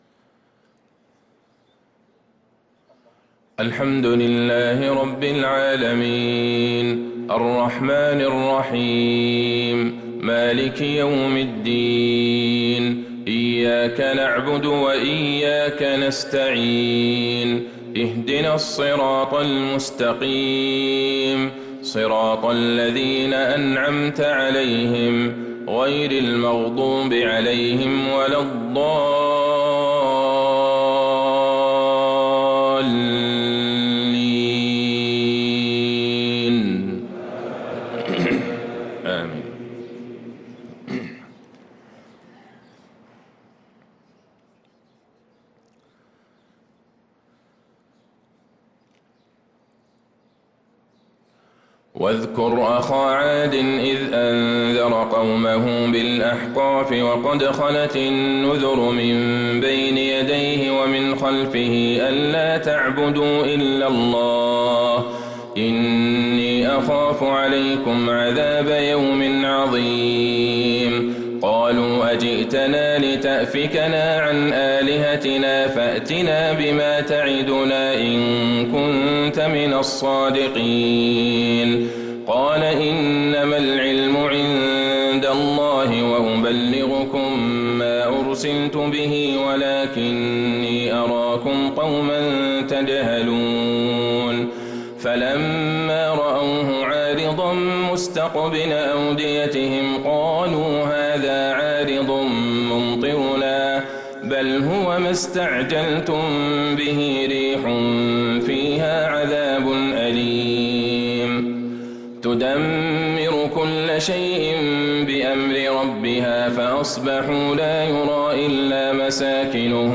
صلاة الفجر للقارئ عبدالله البعيجان 24 شعبان 1445 هـ